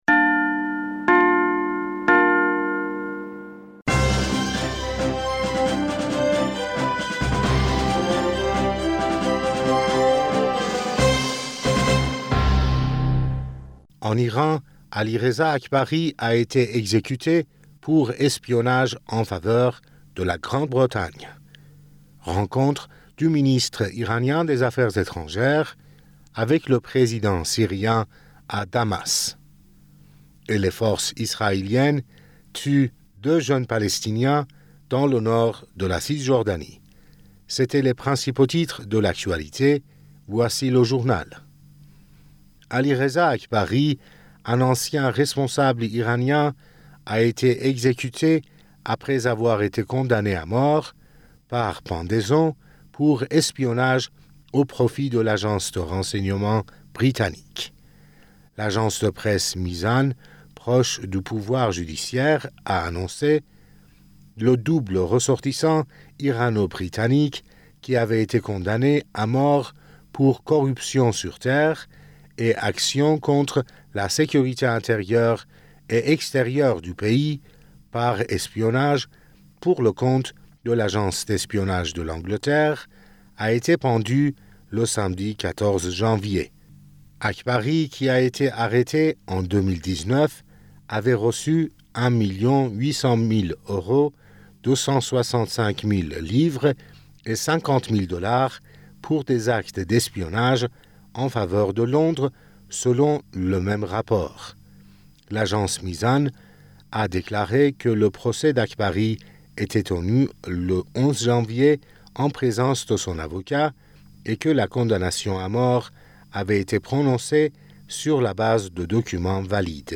Bulletin d'information du 14 Janvier